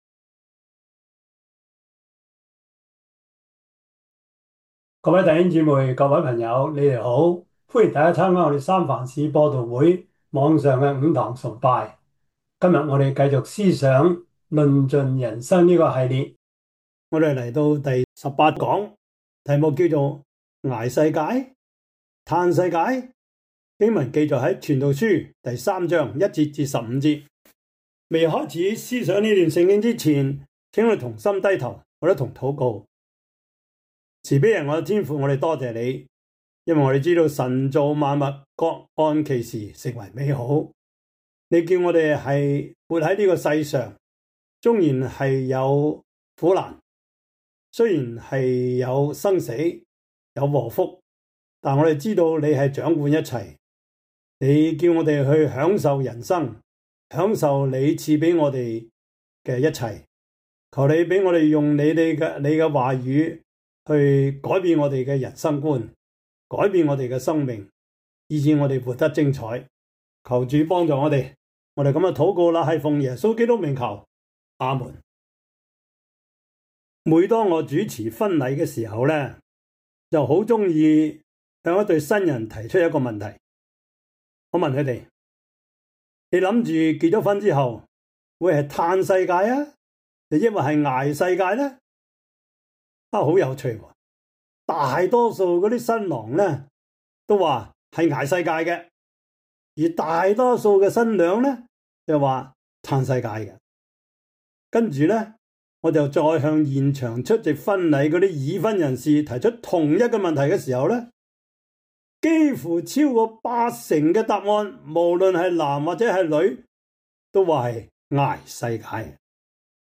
傳道書 3:1-15 Service Type: 主日崇拜 傳道書 3:1-15 Chinese Union Version